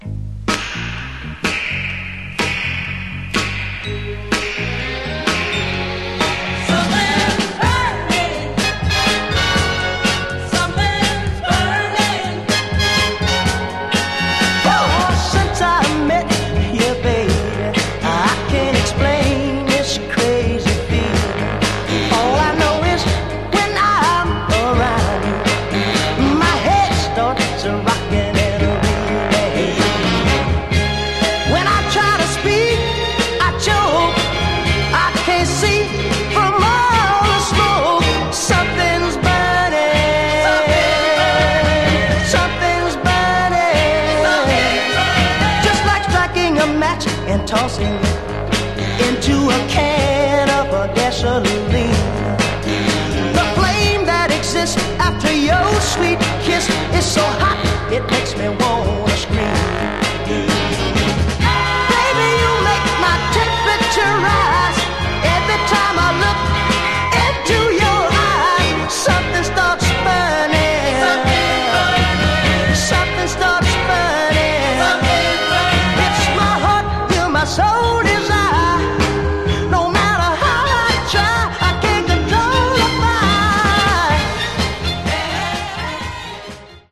Genre: Northern Soul, Motown Style